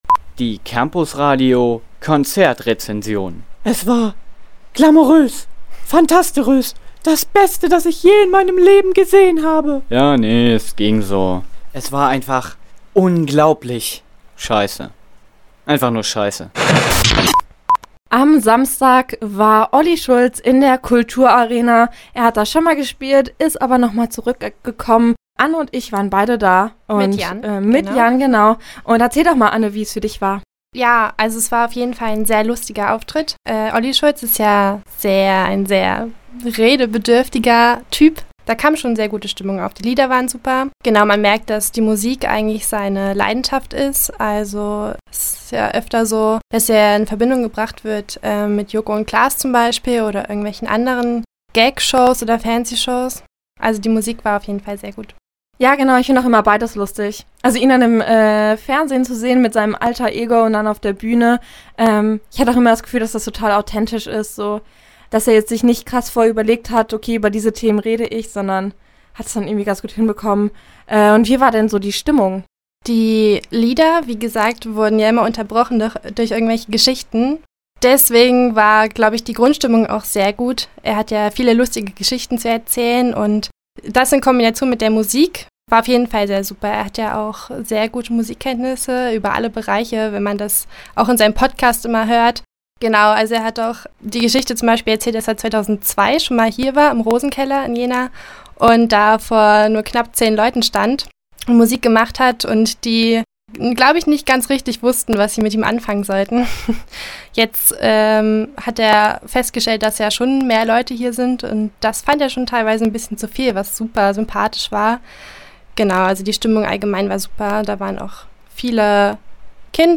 Konzertrezension: Olli Schulz – Campusradio Jena